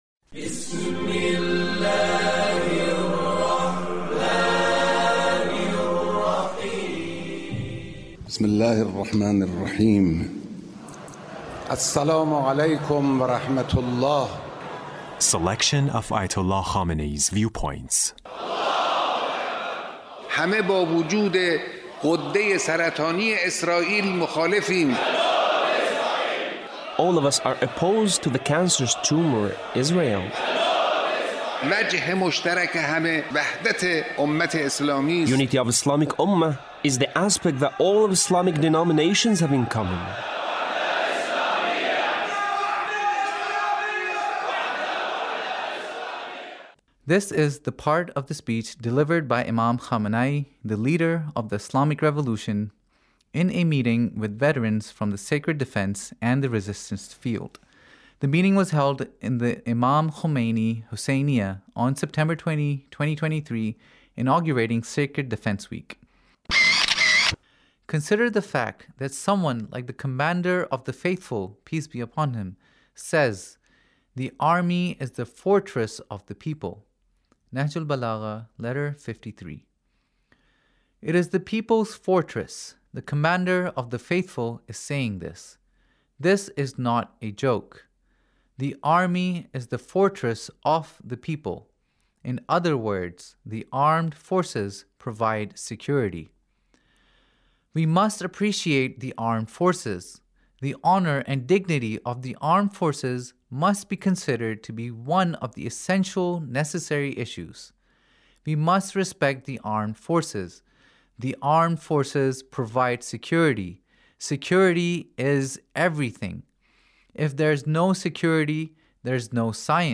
Leader's Speech with Government Officials